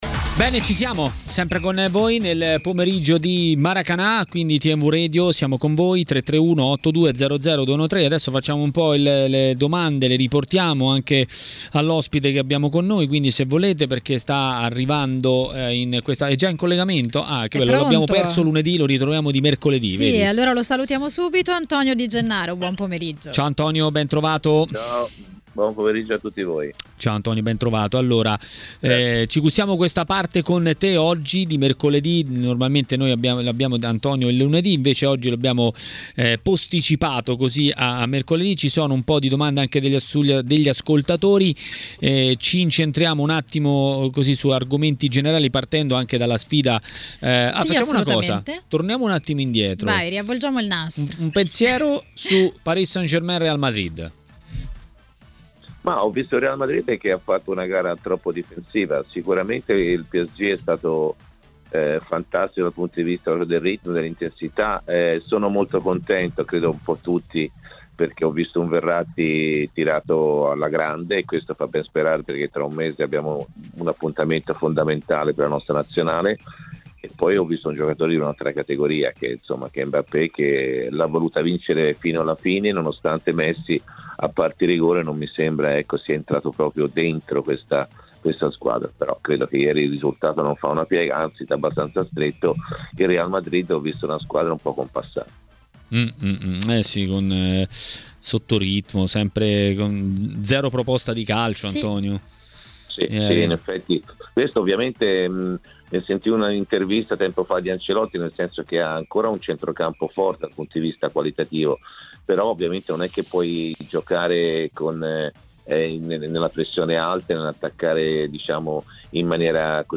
A Maracanà, nel pomeriggio di TMW Radio, è arrivato il momento di Antonio Di Gennaro, ex calciatore e commentatore tv.